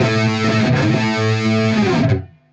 Index of /musicradar/80s-heat-samples/95bpm
AM_HeroGuitar_95-A02.wav